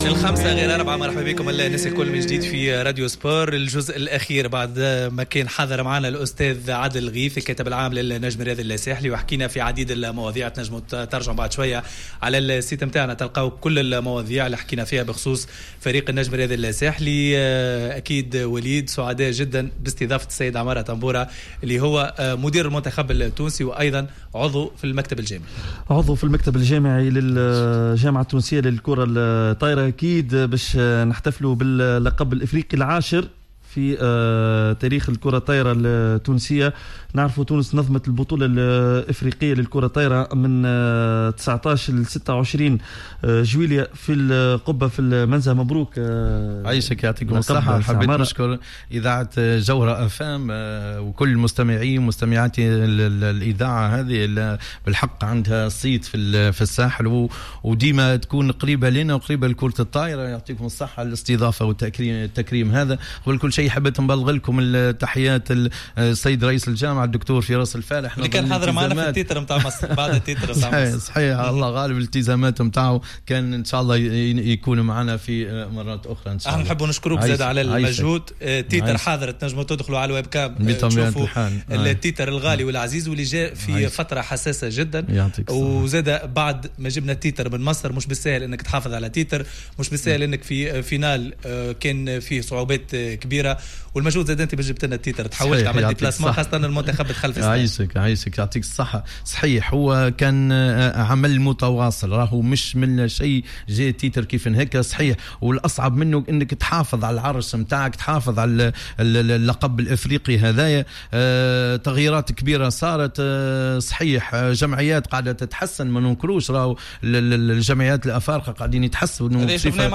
كأس افريقيا للكرة الطائرة في استوديو جوهرة أف أم